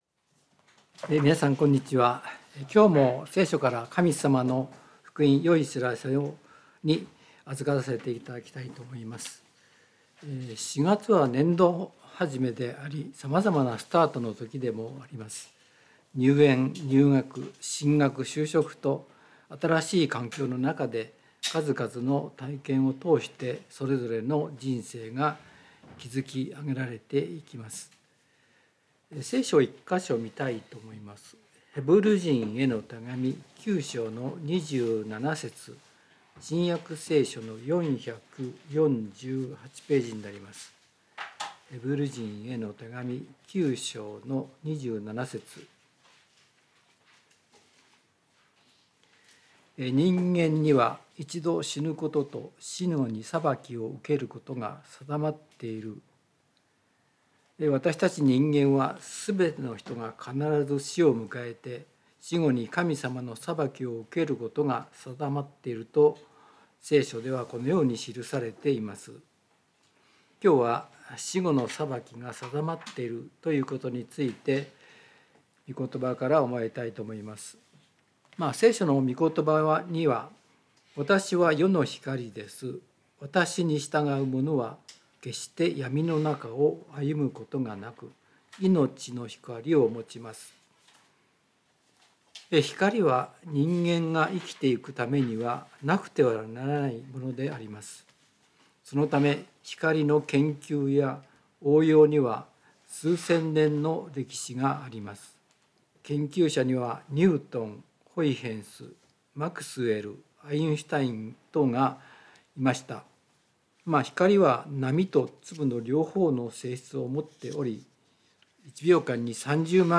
聖書メッセージ No.263